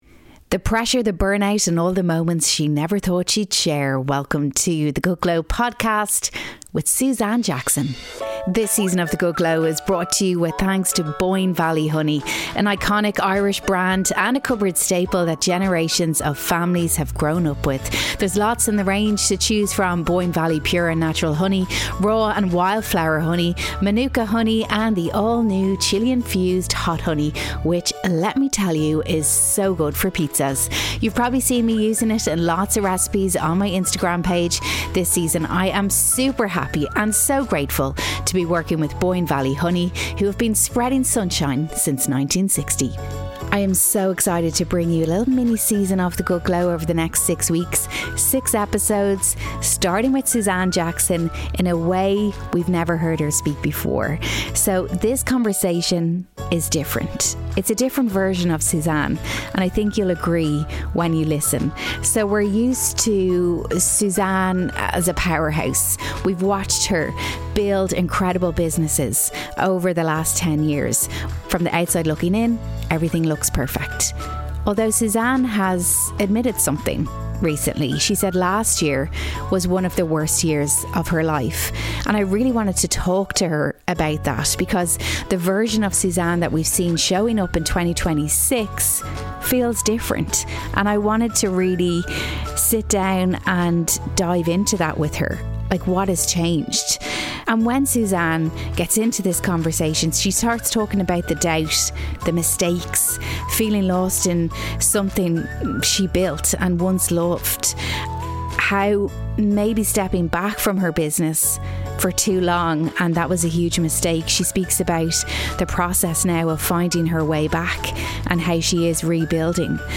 After sharing that 2025 was her toughest year yet, I wanted to go to her office to speak about what's going on behind the scenes.